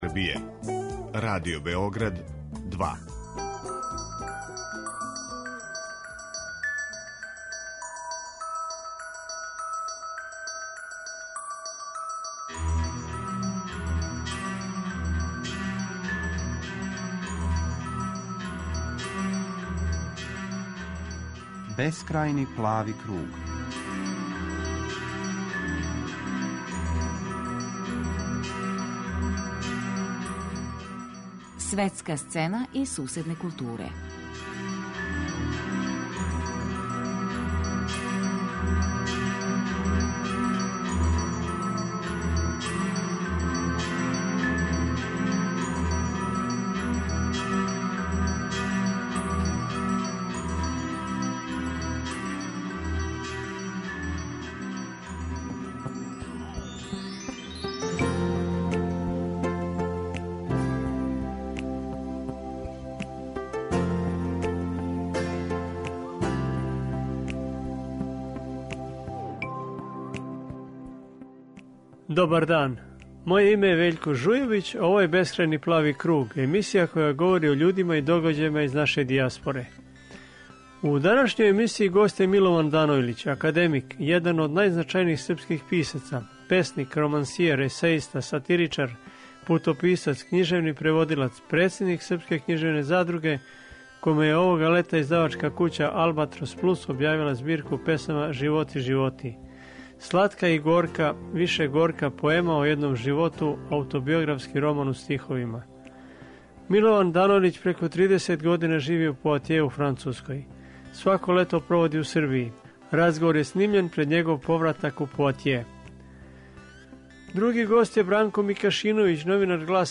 Разговор је снимљен пред његов повратак у Поатје.